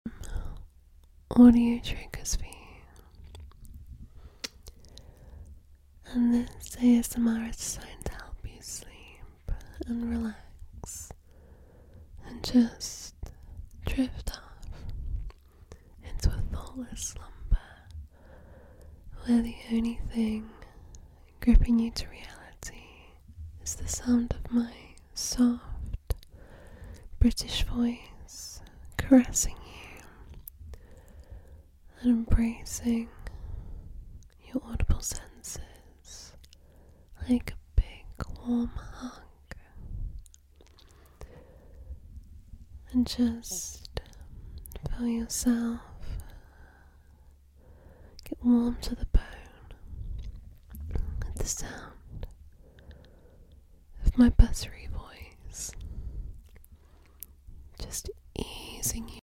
let me make you happy and give you tingles with my whispering ASMR…